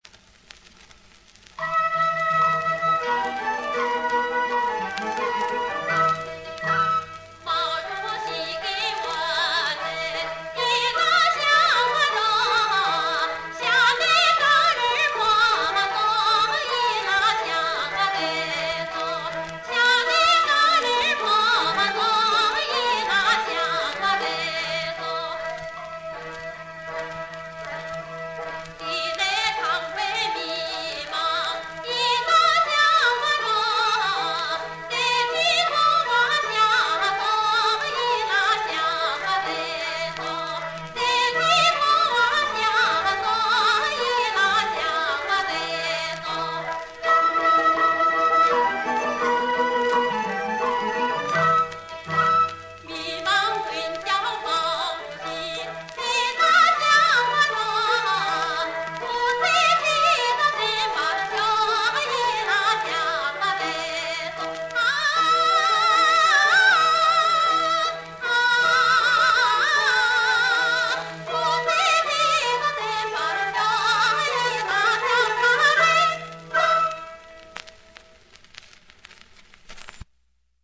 西藏民歌